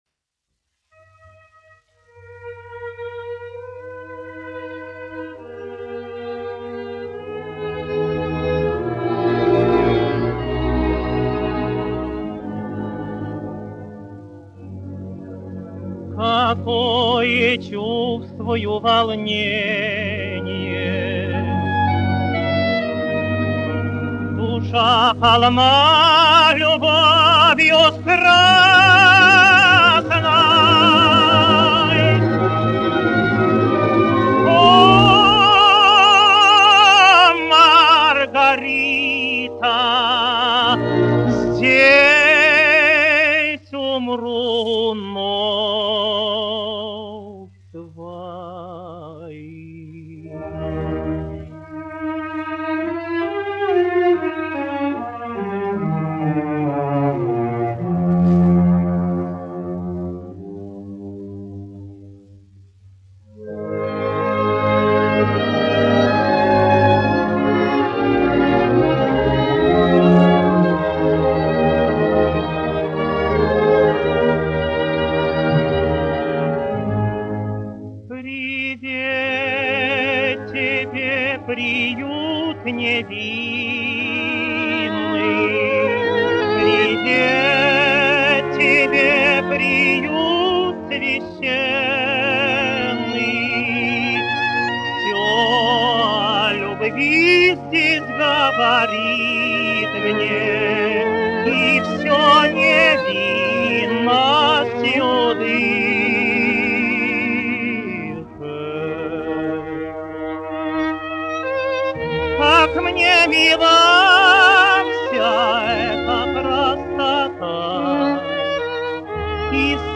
1 skpl. : analogs, 78 apgr/min, mono ; 25 cm
Operas--Fragmenti
Skaņuplate